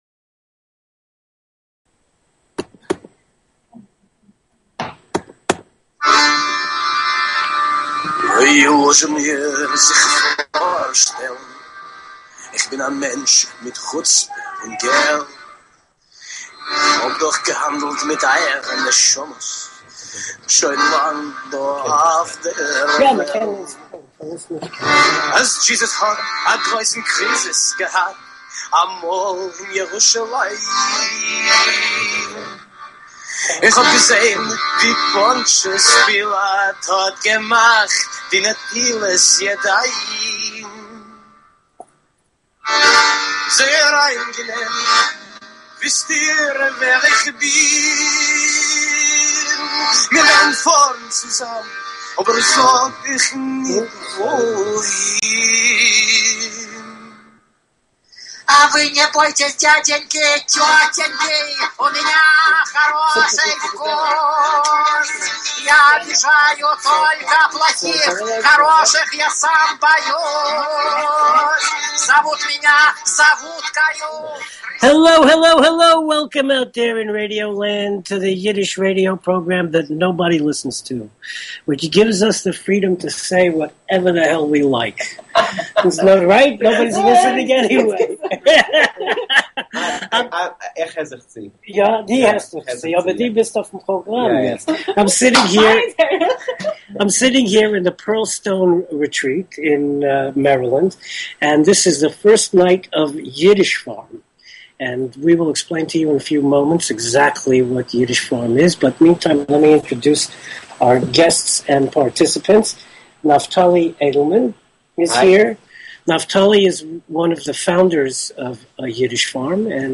Talk Show Episode, Audio Podcast, New_Yiddish_Rep_Radio_Hour and Courtesy of BBS Radio on , show guests , about , categorized as
A forum for Yiddish Culture on internet radio. Talk radio in Yiddish, in English, sometimes a mix of both, always informative and entertaining. NYR Radio hour will bring you interviews with Yiddish artists, panel discussions, radio plays, comedy, pathos, and a bulletin board of events, as well as opinions and comments from listeners.